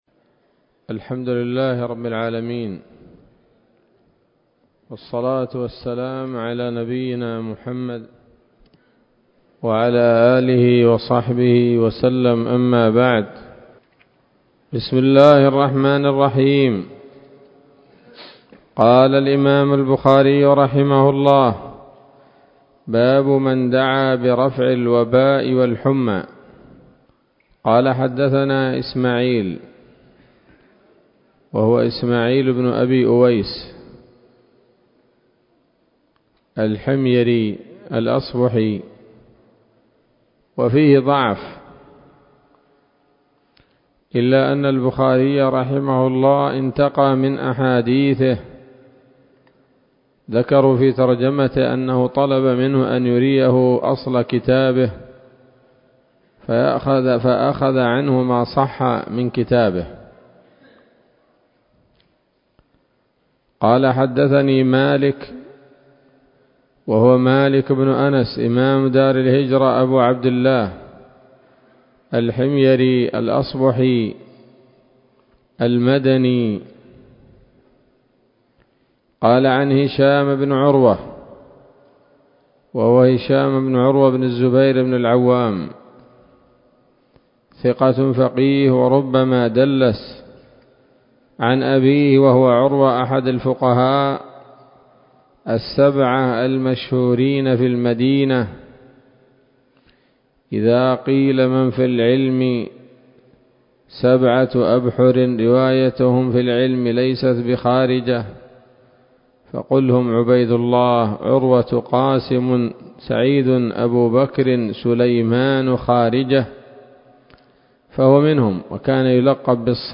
الدرس السابع عشر وهو الأخيرمن كتاب المرضى من صحيح الإمام البخاري